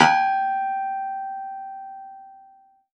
53x-pno06-G3.wav